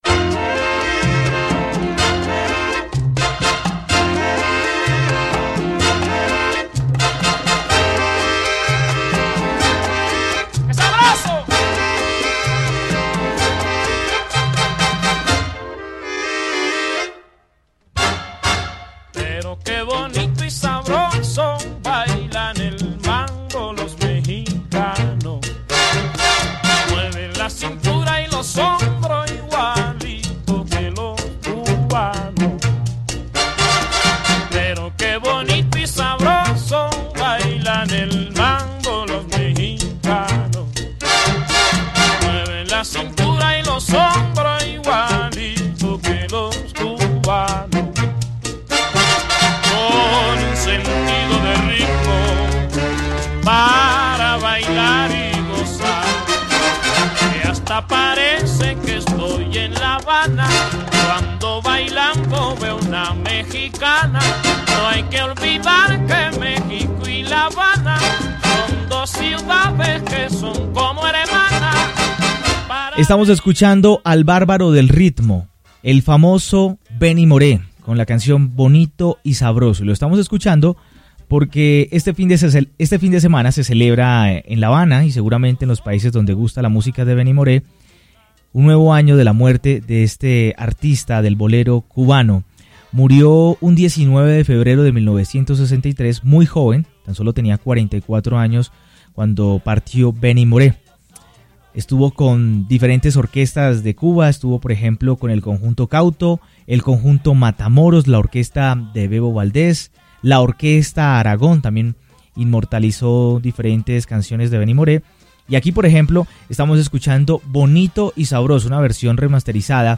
con participación de estudiantes de la Facultad de Ciencias de la Comunicación.